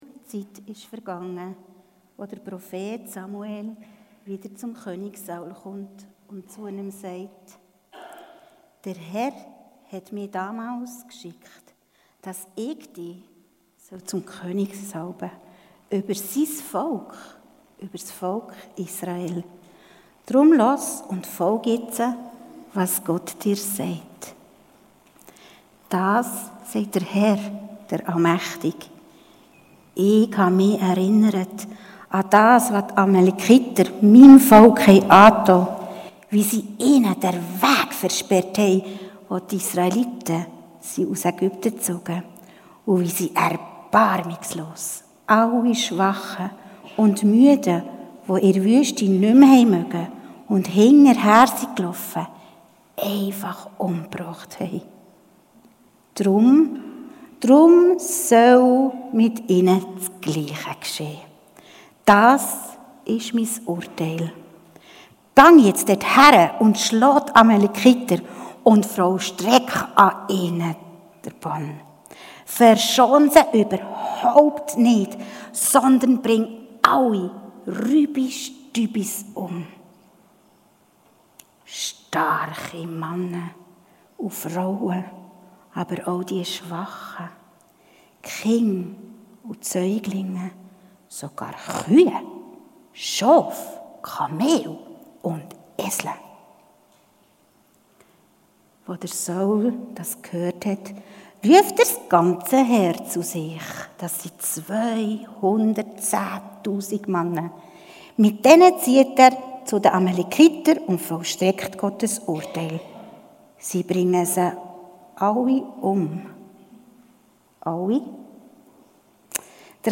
Predigten, Interviews und Messages